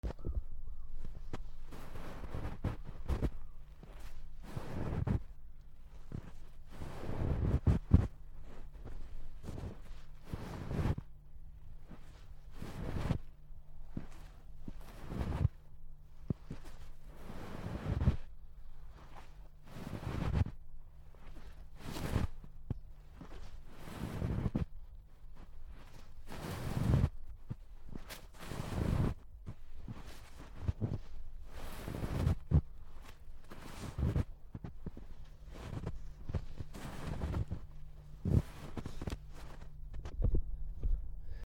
ゆっくり埋まる
/ M｜他分類 / L35 ｜雪・氷 /
MKH416